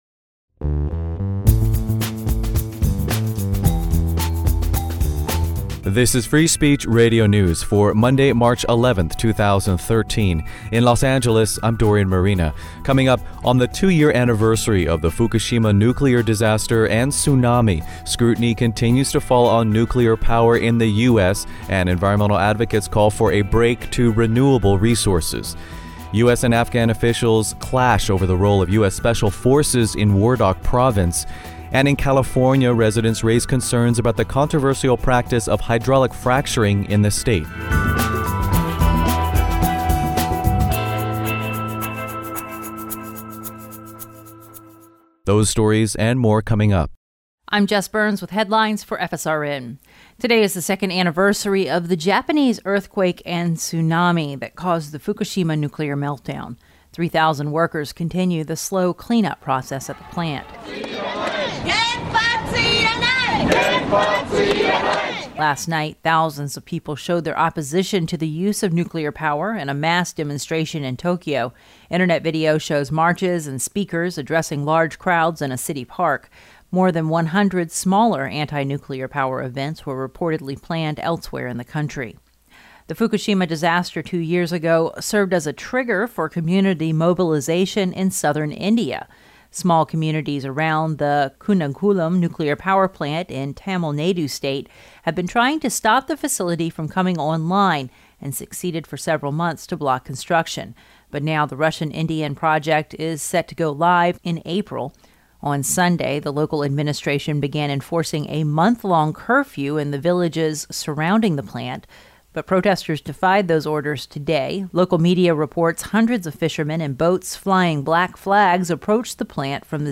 Newscast for Monday, March 11, 2013